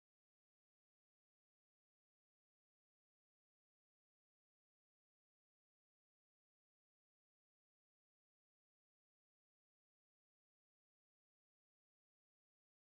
Navy_Pluck.wav